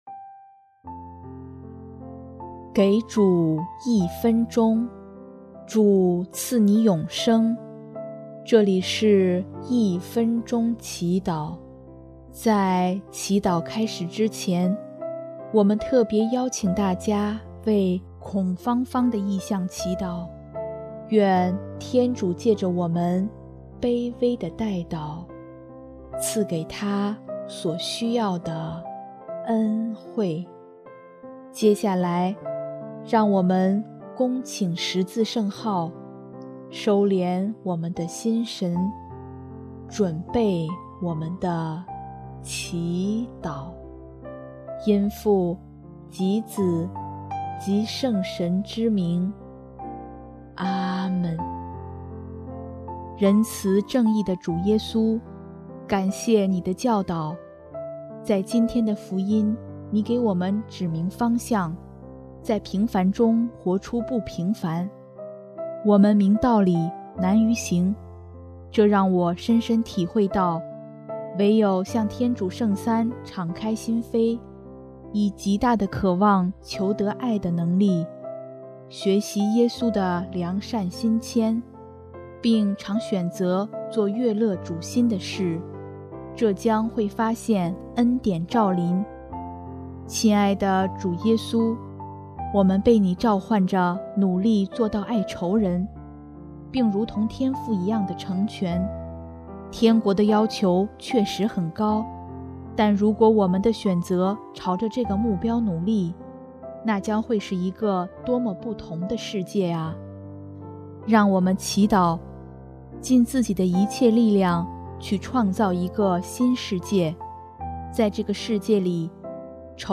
【一分钟祈祷】|6月17日 尽力达致成全